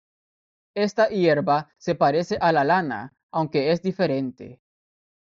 Pronounced as (IPA) /ˈʝeɾba/